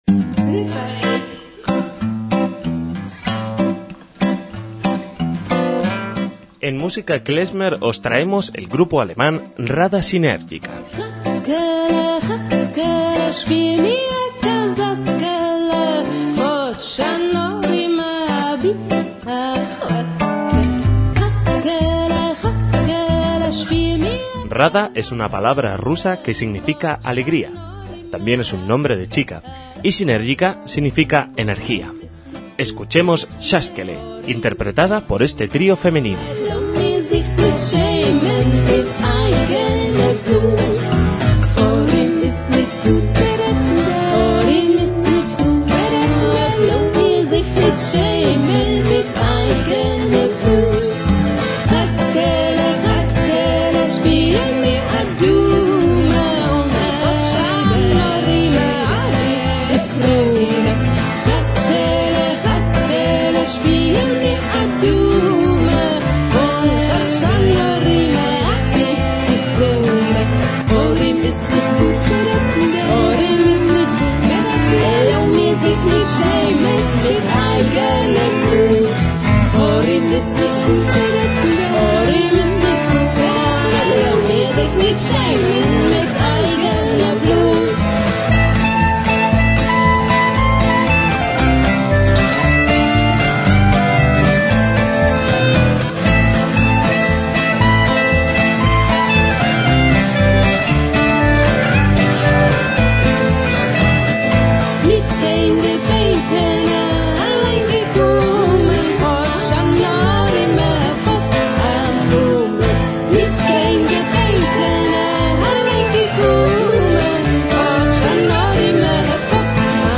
MÚSICA KLEZMER